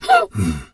zebra_breath_wheeze_01.wav